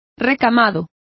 Complete with pronunciation of the translation of embroideries.